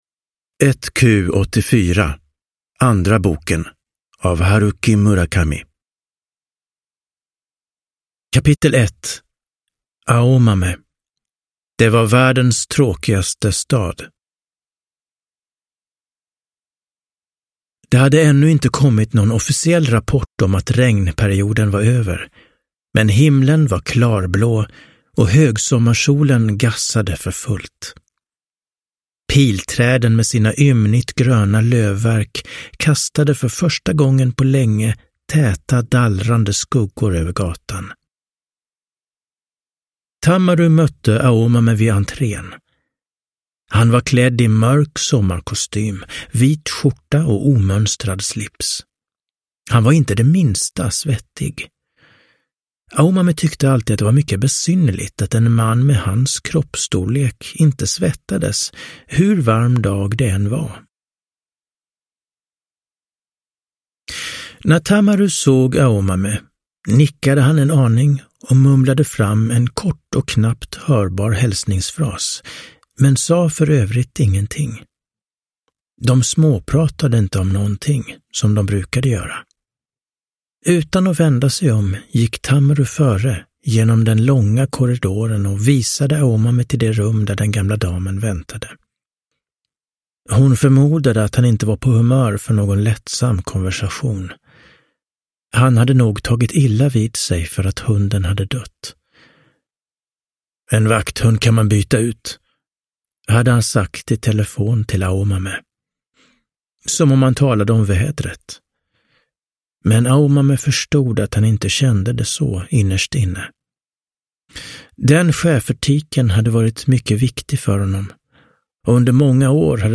1Q84 : andra boken : juli-september – Ljudbok – Laddas ner